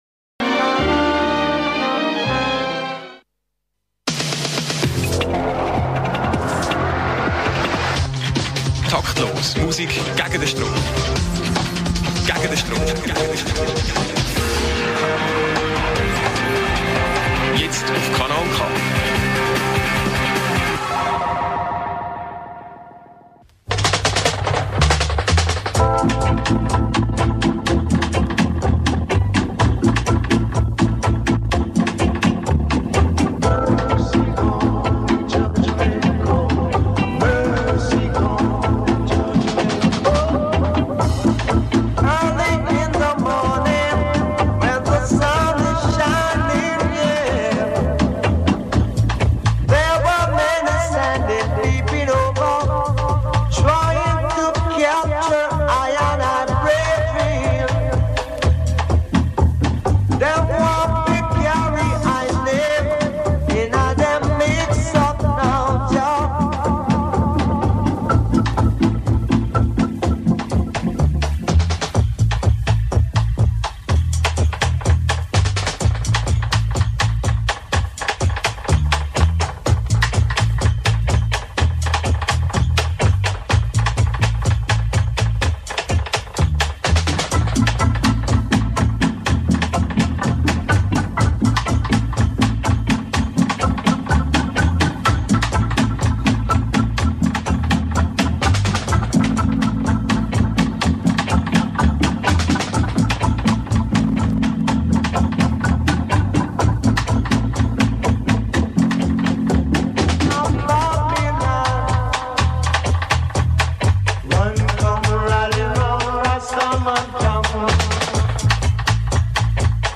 monthly Reggae Dub Radio show